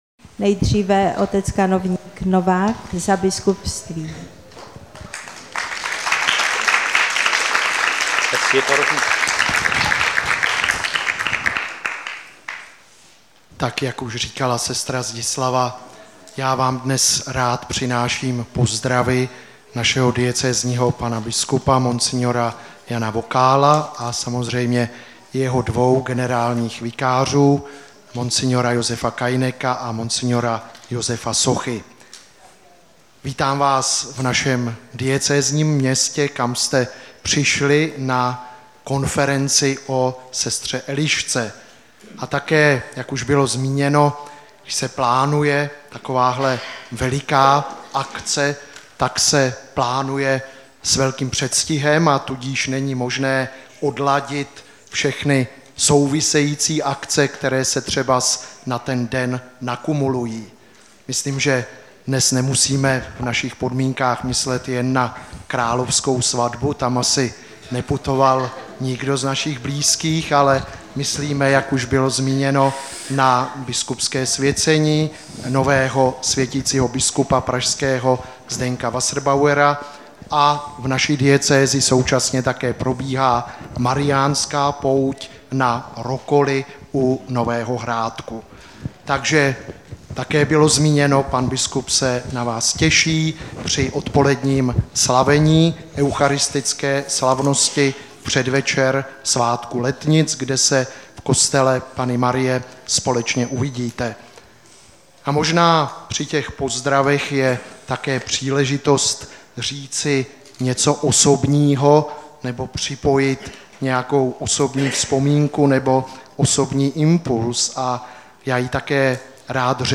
Přednášky z konference (mp3)